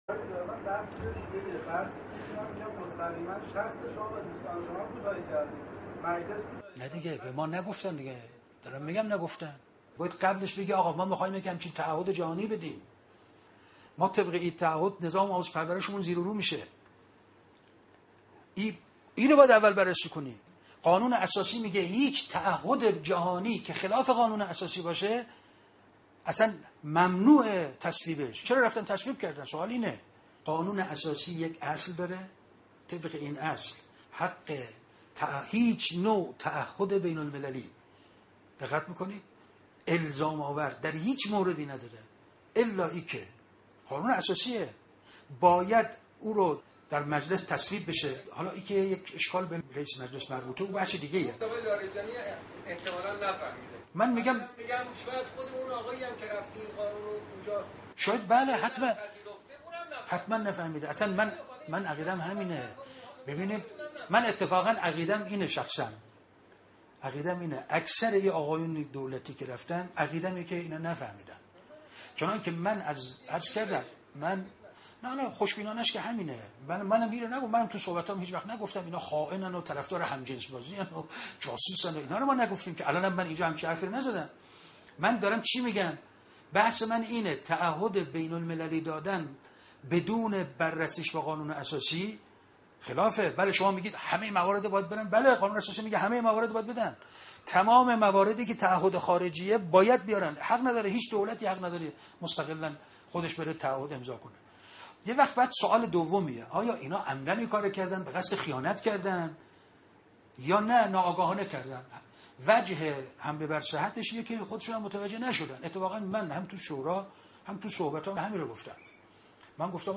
به گزارش گروه دانشگاه خبرگزاری تسنیم، در پی انتشار سخنان تقطیع شده استاد رحیم پور ازغدی در جمع اساتید دانشگاه، گروهی از تشکل‌های دانشجویی با تنظیم شکایت از روزنامه دولتی ایران و پایگاه های خبری" انتخاب" و... همچنین تعقیب قضایی شخص مونتاژگر، از دادستان تهران درخواست کردند با افراد و رسانه‌هایی که اقدام به تحریف و انتشار آن کردند، برخورد قانونی داشته باشد.